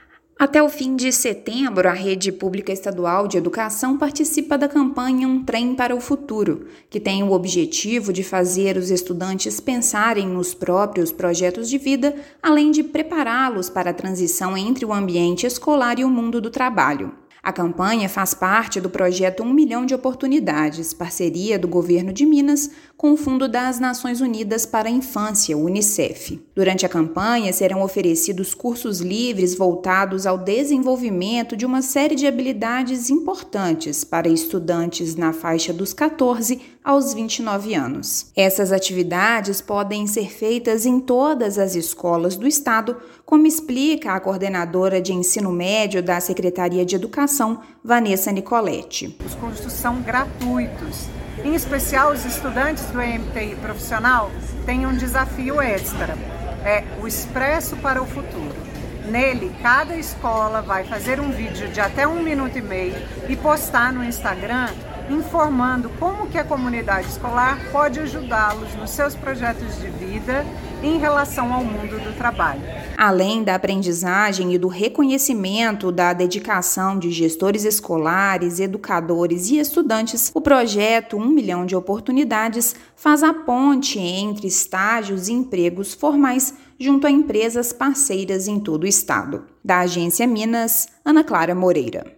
“Um Trem para o Futuro” prevê a interação dos estudantes do ensino médio com a comunidade escolar, voltado a projetos de vida apresentados pelos jovens. Ouça matéria de rádio.